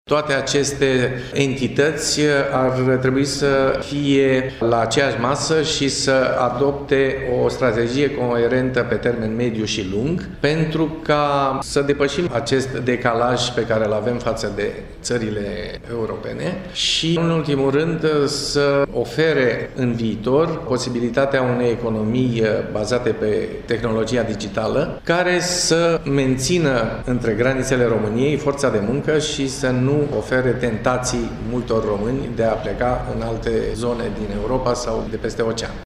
El a precizat, la o masă rotundă, că este nevoie de o strategie coerentă în acest domeniu, care să unească industria, Ministerul Educaţiei, pe cel al Comunicaţiilor şi mediul academic.